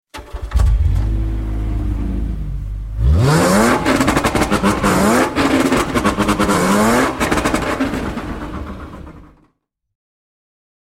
Car Engine Start: Realistic Sports Car Audio
Car Engine Start & Acceleration Sound Effect
Description: Car engine start & acceleration sound effect. Engine revving sound. Hear a powerful sports car engine ignite and roar as it accelerates to full throttle.
Car-engine-start-and-acceleration-sound-effect.mp3